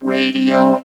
68.4 VOCOD.wav